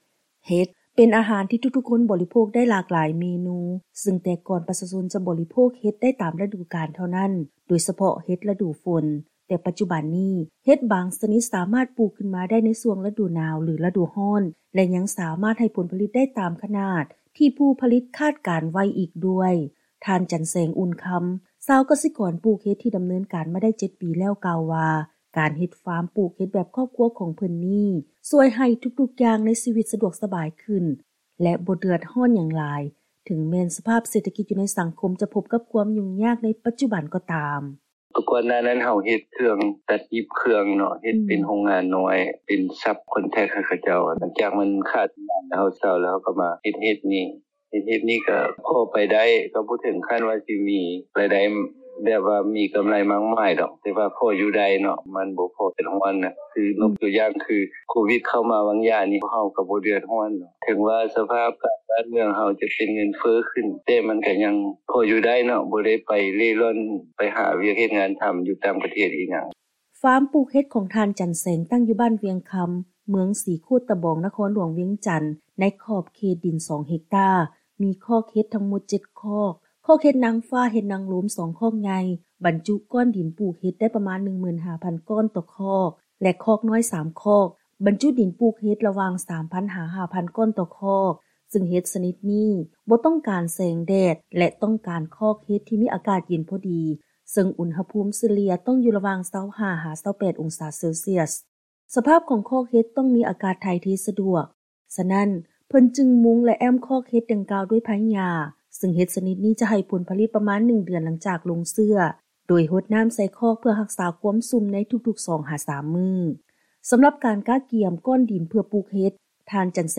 ເຊີນຟັງລາຍງານກ່ຽວກັບ ຫັນປ່ຽນອາຊີບມາສ້າງຟາມປູກເຫັດຂາຍ ເພື່ອເຮັດໃຫ້ຊີວິດການເປັນຢູ່ດີຂຶ້ນ